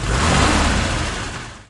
autobahn_motor.ogg